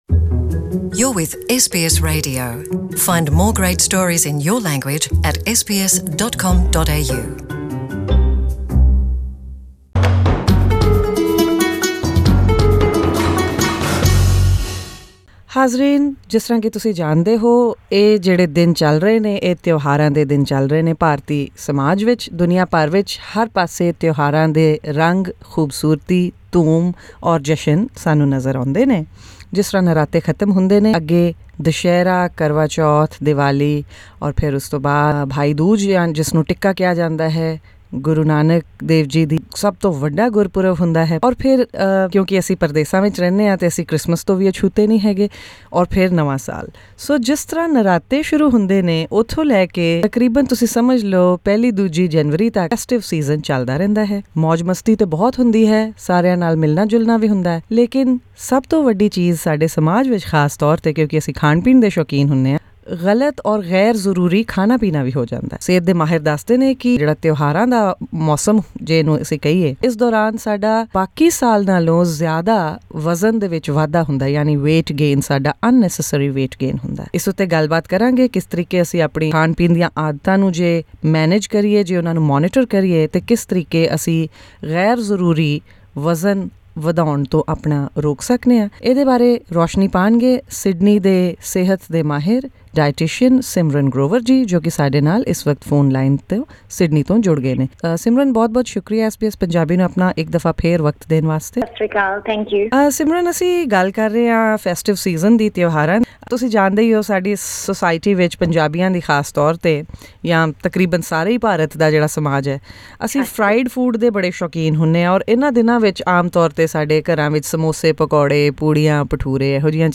To listen to this interview in Punjabi, click on the player at the top of the page.